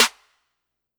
Cardiak_sLexSnare.wav